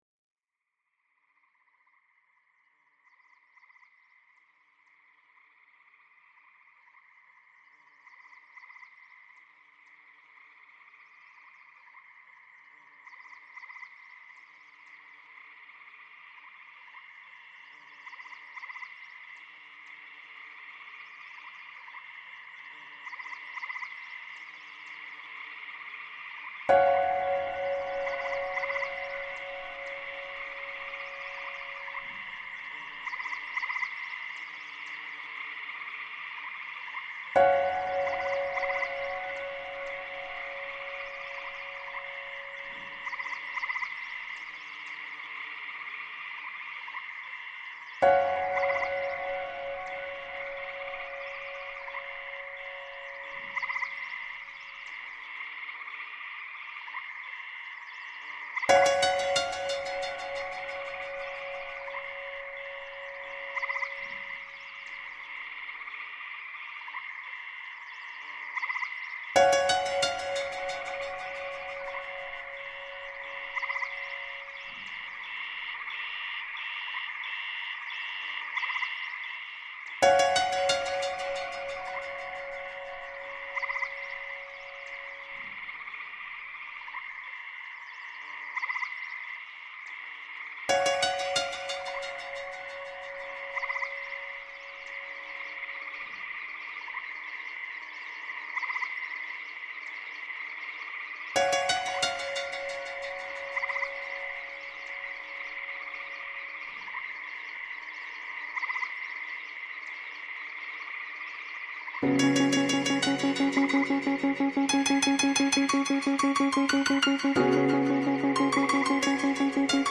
Tainter Creek, Wisconsin reimagined